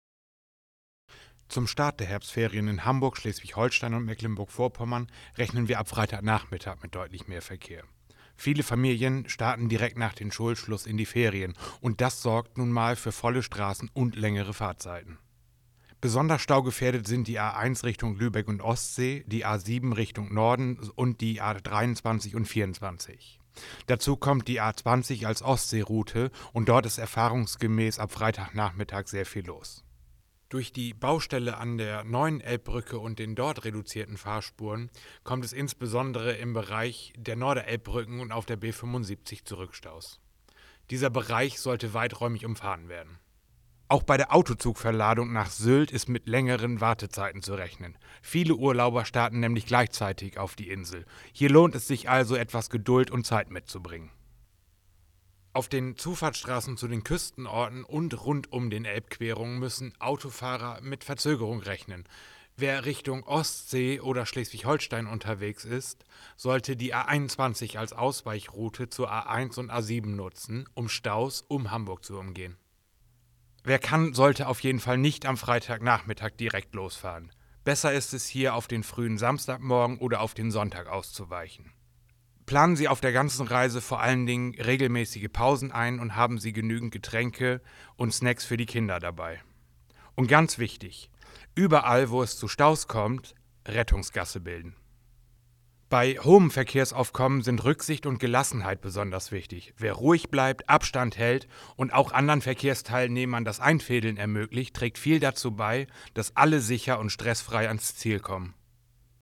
O-Ton
o-ton_stauprognose_herbstferien.mp3